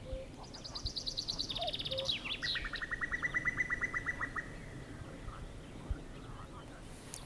Птицы -> Кукушковые ->
кукушка, Cuculus canorus